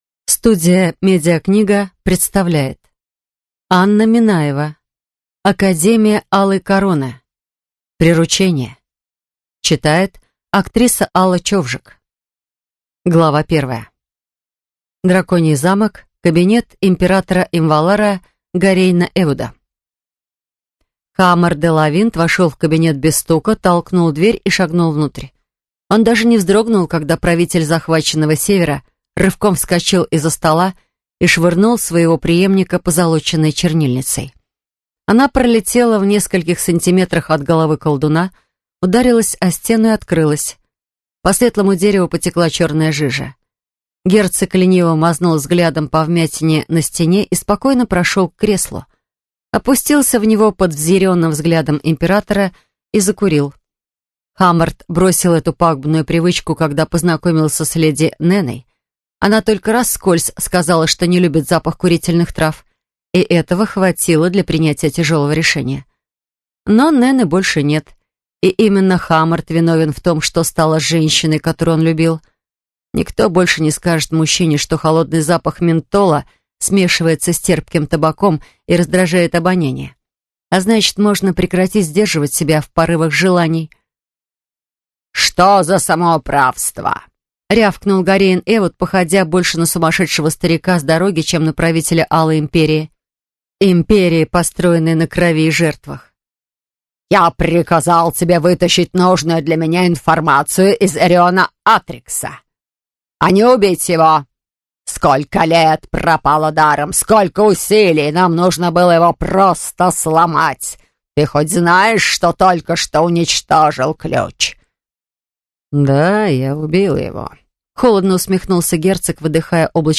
Аудиокнига Академия Алой короны. Приручение | Библиотека аудиокниг